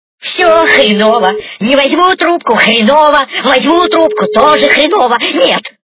» Звуки » Люди фразы » Голос - Все хренова
При прослушивании Голос - Все хренова качество понижено и присутствуют гудки.